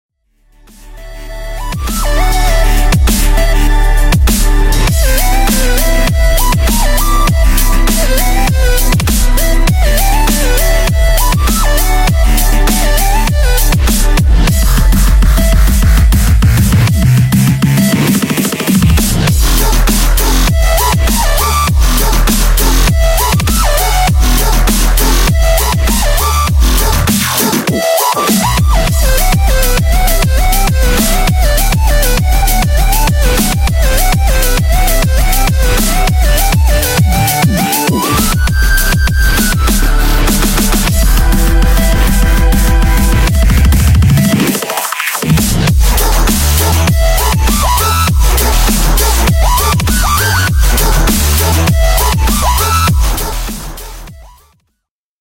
genre:dubstep